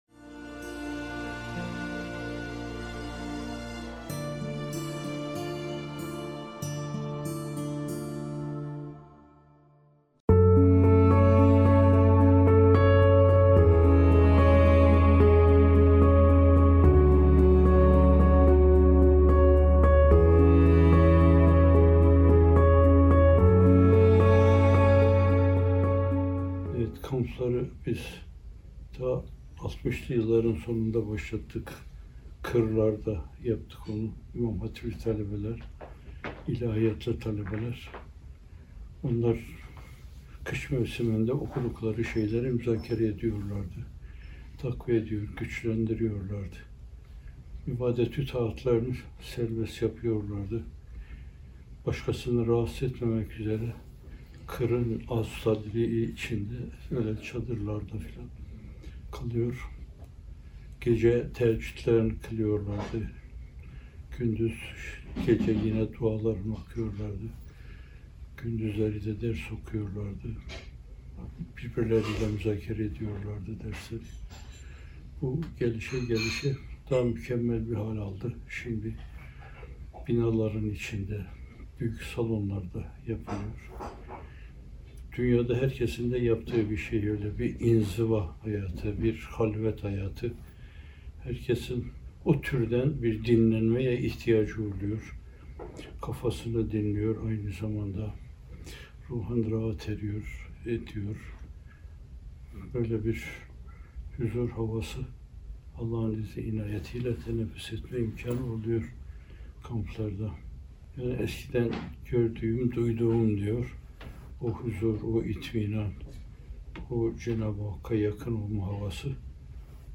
Tesbihatın makamı da Muhterem Hocamıza aittir.